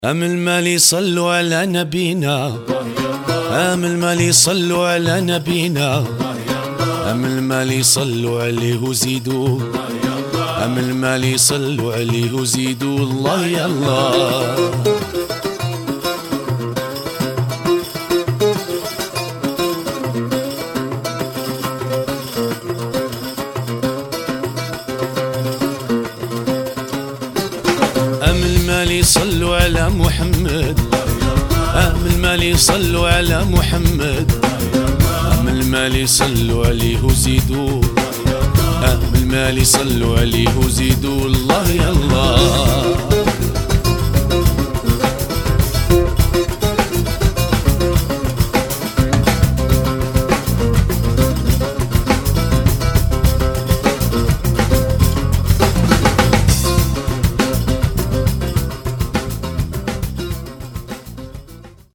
sax
guitar
keyboards, grand piano
bass guitar
percussion
drums
guembri, vocals
berbouka, vocals
mandole, vocals